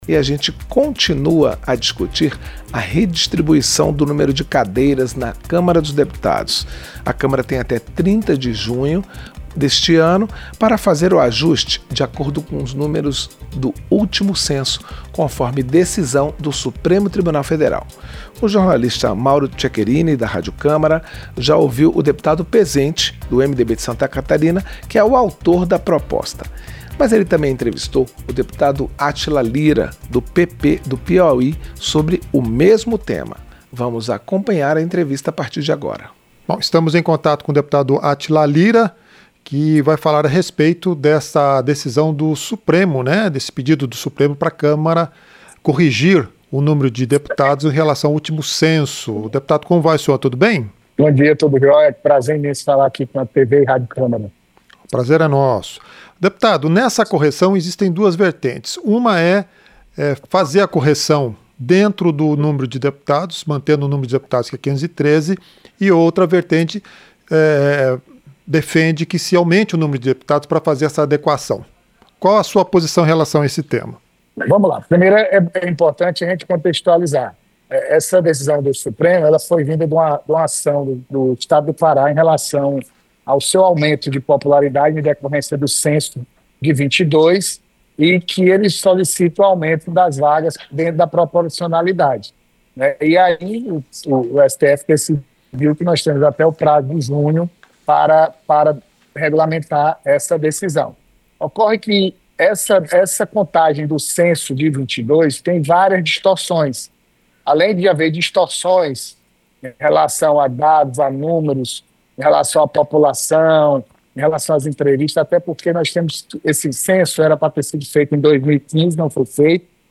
Entrevista - Dep. Átila Lira (PP-PI)